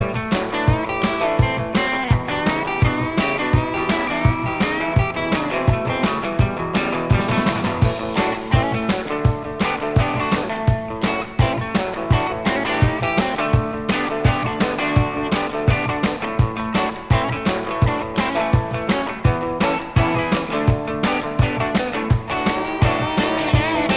Minus Bass Guitar Rock 2:22 Buy £1.50